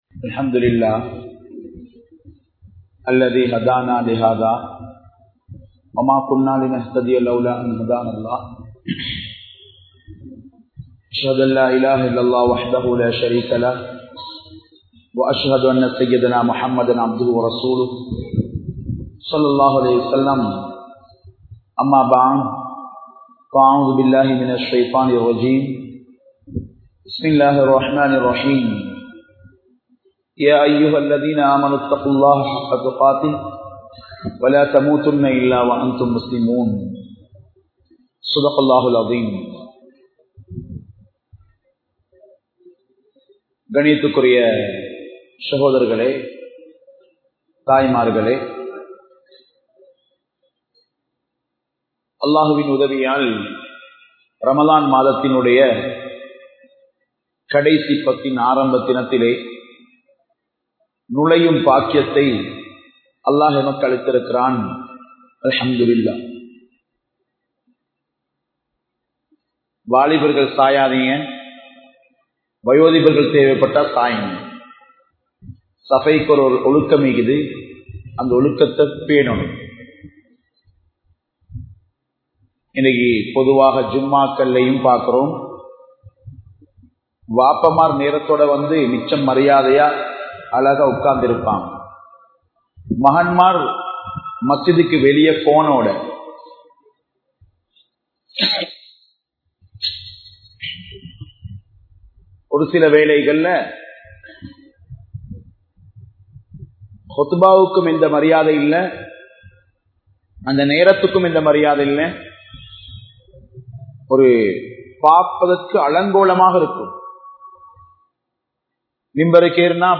Vetkam Edupattathan Vilaivu (வெட்கம் எடுபட்டதன் விளைவு) | Audio Bayans | All Ceylon Muslim Youth Community | Addalaichenai
Kurunegala, Mallawapitiya Jumua Masjidh